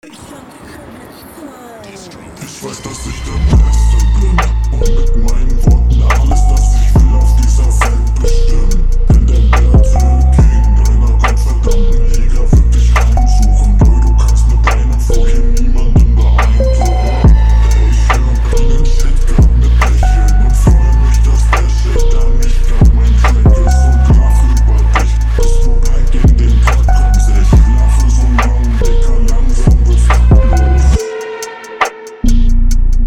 Sorry bro nicht geil man versteht kaum etwas, das was ich verstanden habe war semi-mäßig …